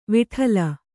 ♪ viṭhala